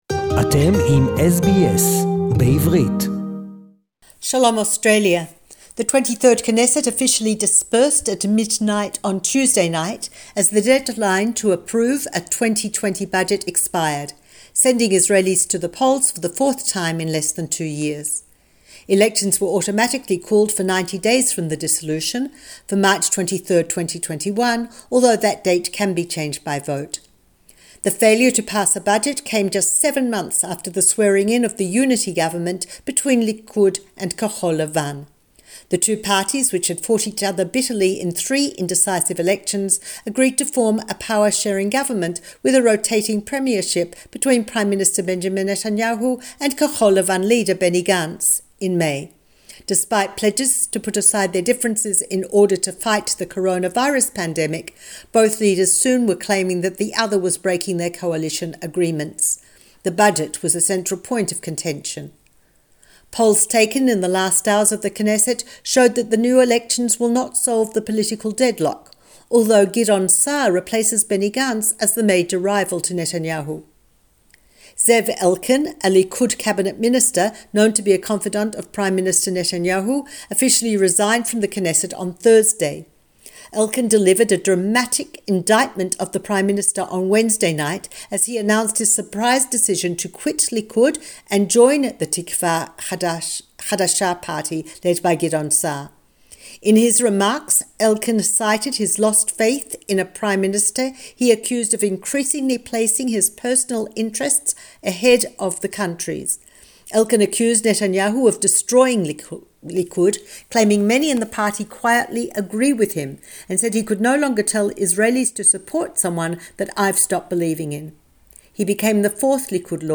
The dissolution of the Knesset and the vaccination drive to combat Covid 19 SBS Jerusalem report in English